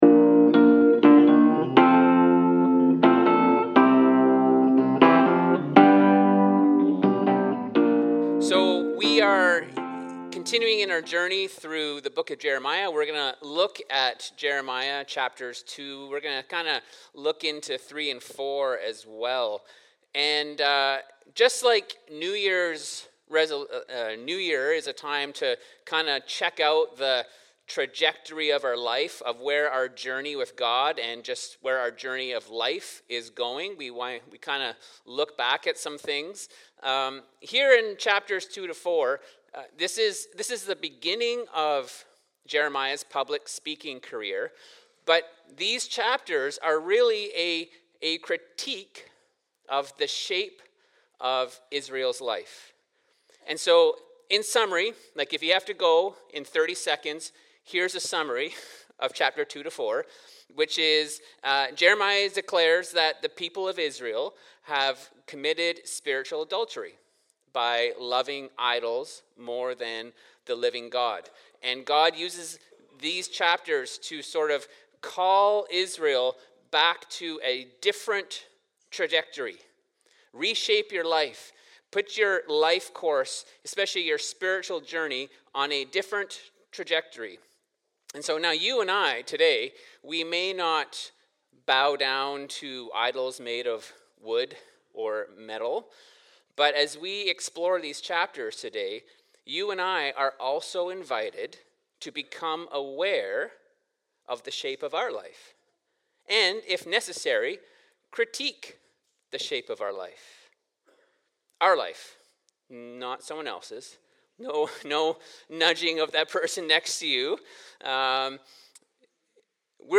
Sermons | Lighthouse Church